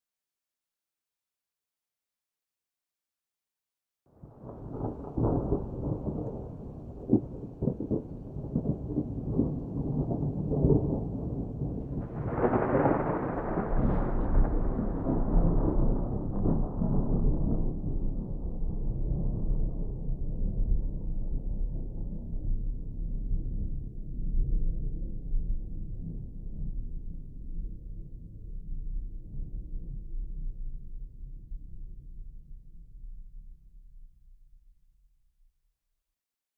thunderfar_3.ogg